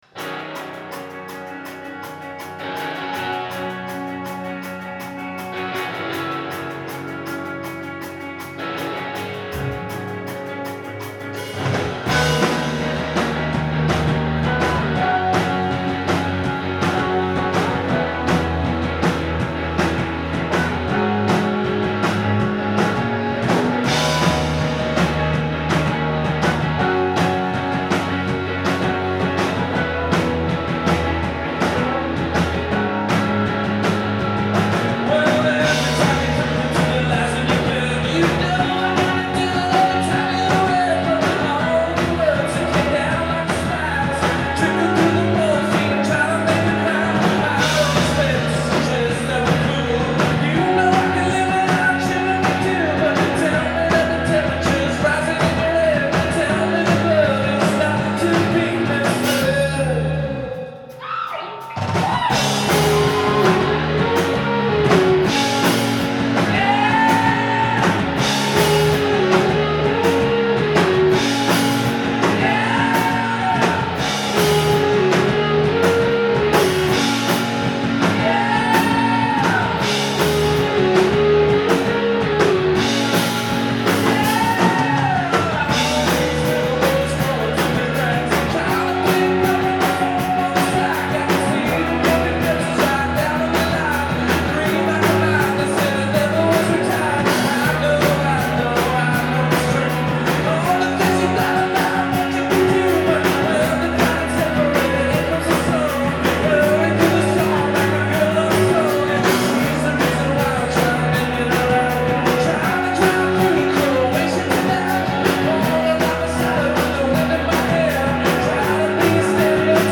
Live at TT the Bears
in Cambridge, Massachusetts